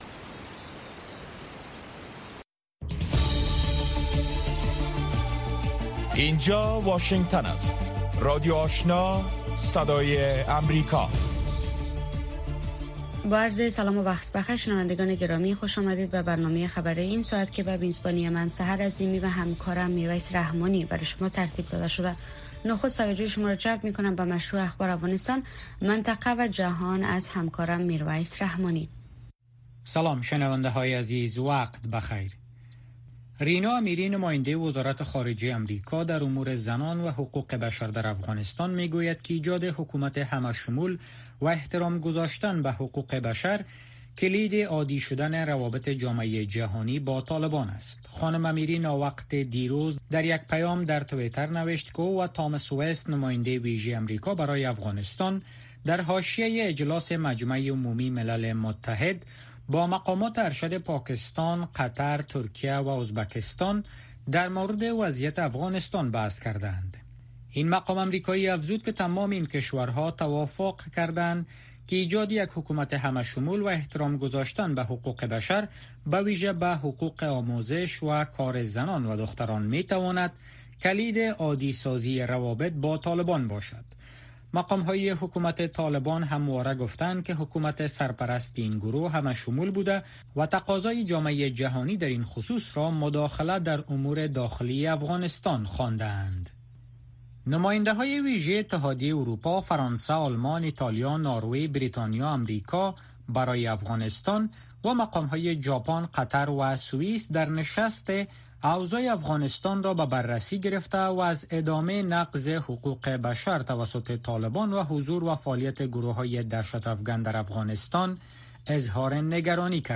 برنامۀ خبری شامگاهی